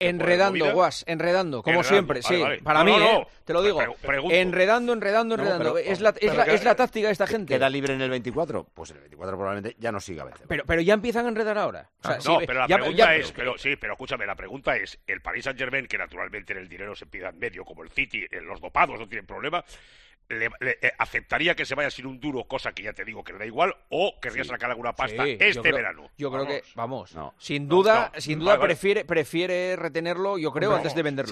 AUDIO: El presentador de 'El Partidazo de COPE' opina sobre la noticia que adelantó L'Equipe por la decisión del delantero francés con su futuro en Francia